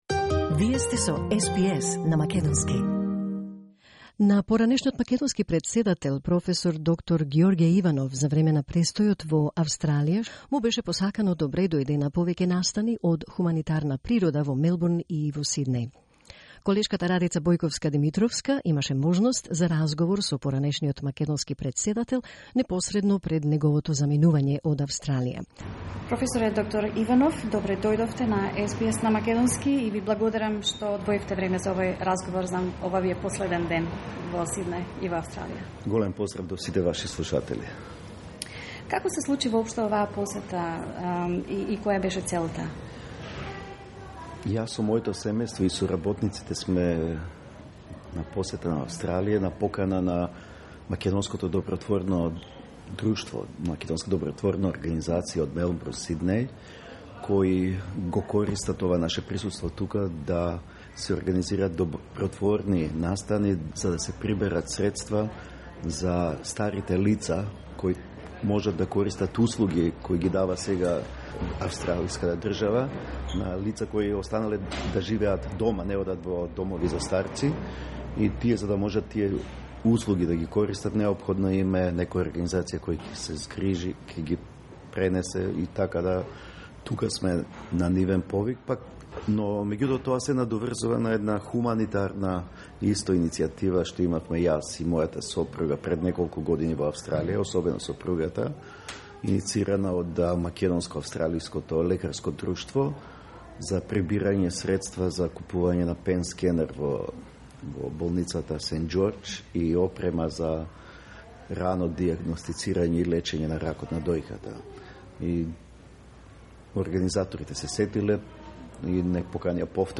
'All of my arguments were stated in my address to the UN General Assembly in 2018. It was stated that our right to self-determination had been violated', says Macedonian Former President, Prof Dr.Gjorge Ivanov who refused to put his signature on the Macedonian-Greek name deal, in an interview for SBS Macedonian during his recent visit to Australia